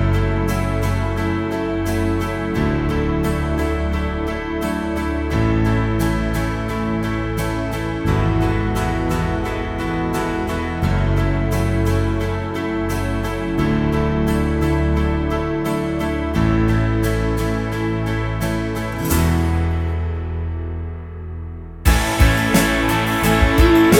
Clean Pop (2010s) 4:14 Buy £1.50